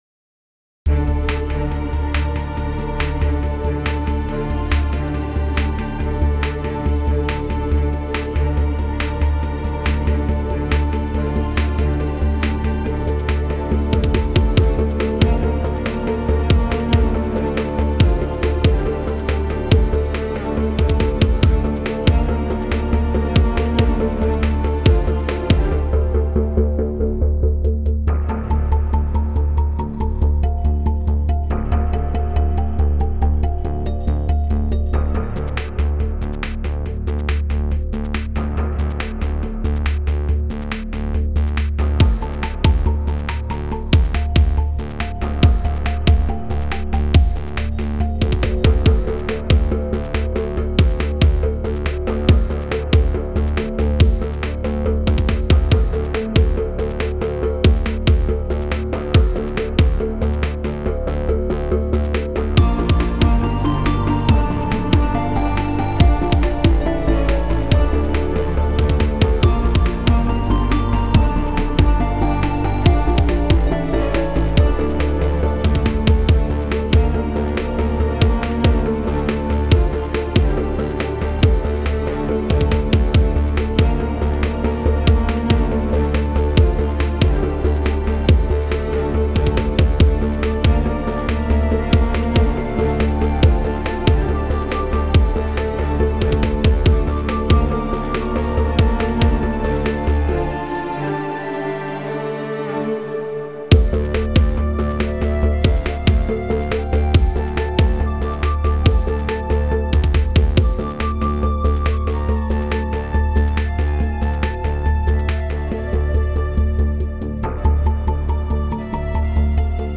an electronic rendition
put into a minor key for fun and profit.